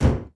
ladder2.wav